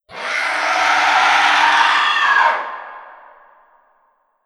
effect__ghost_catch.wav